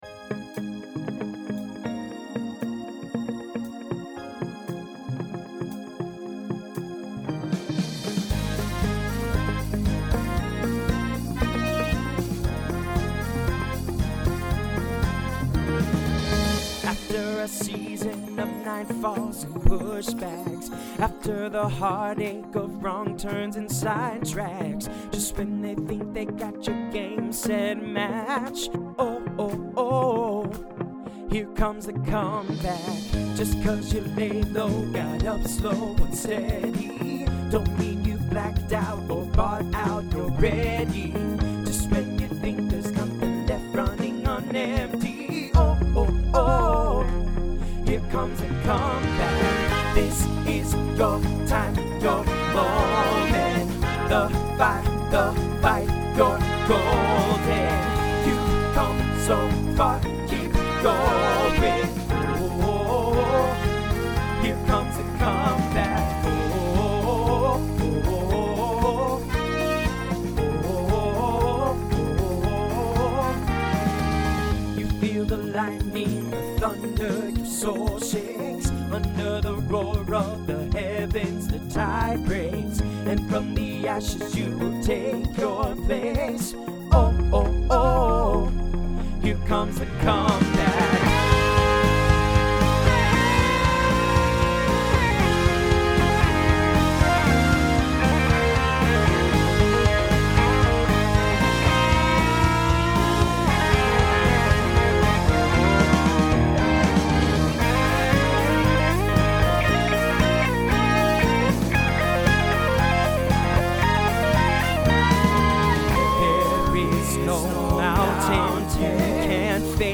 Pop/Dance , Rock
Mid-tempo Voicing TTB